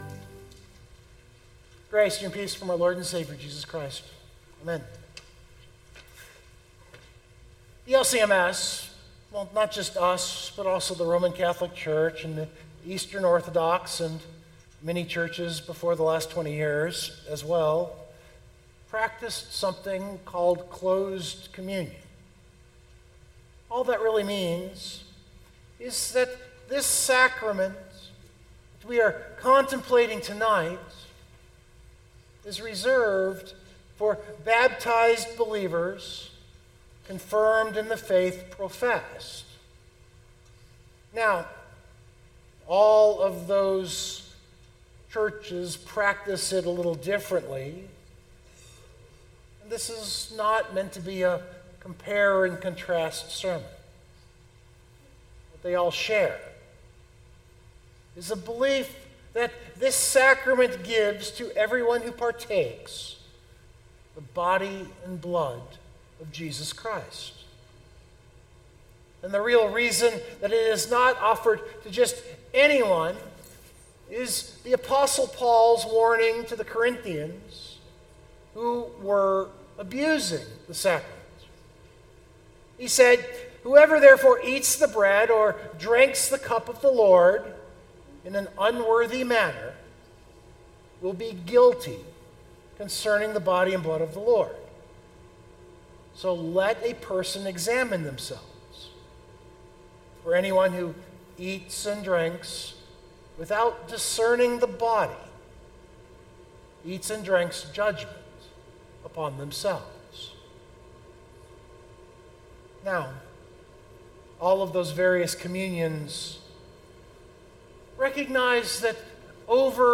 This sermon to me is a meditation on that tradition as I have received it and attempted to steward it. It ponders the biblical reality of Judas at that table, with a contrast with Peter.